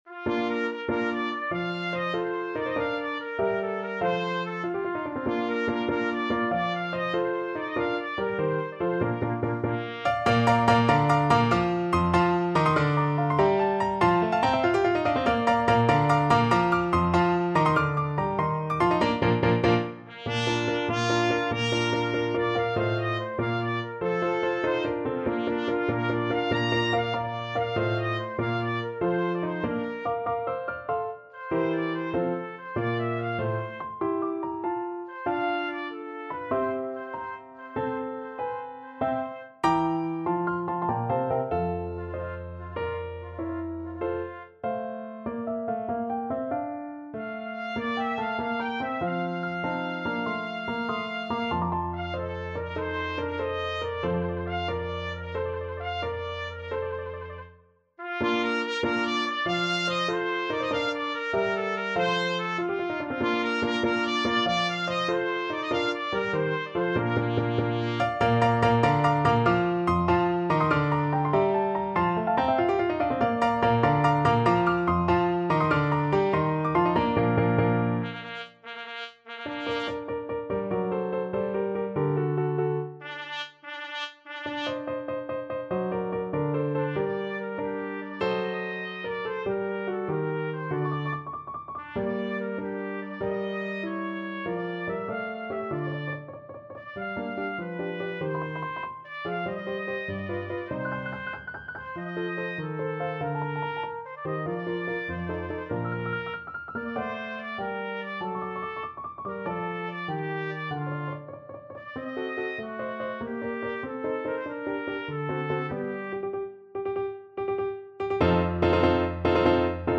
Trumpet version
6/8 (View more 6/8 Music)
Rondo: Allegro .=96 (View more music marked Allegro)
Classical (View more Classical Trumpet Music)